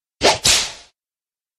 Latigo
Tags: botonera de programa la nave invisible fatality